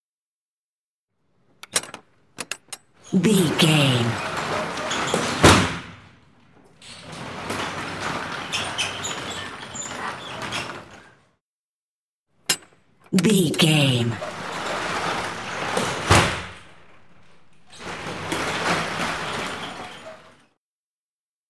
Van cargo rear door slide
Sound Effects